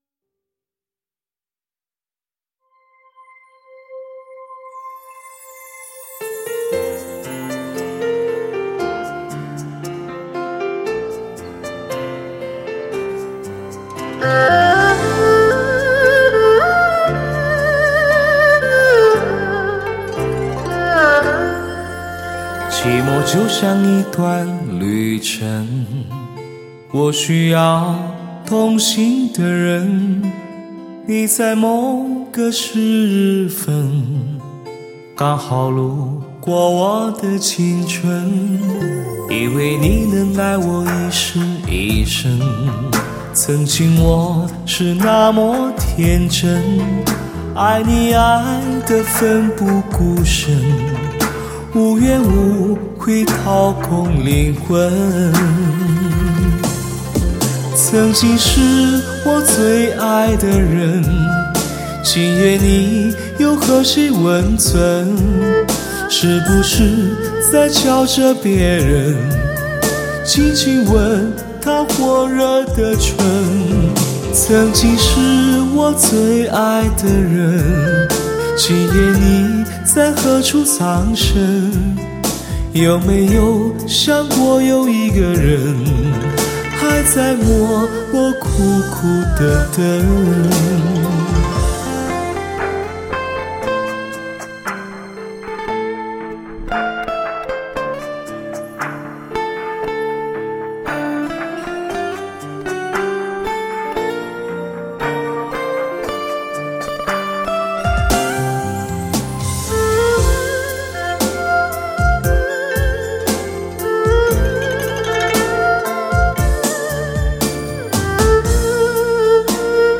空气感和层次感兼顾，细节还原和定位感全面提升，